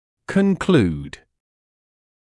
[kən’kluːd][кэ’kluːd]делать вывод, приходить к заключению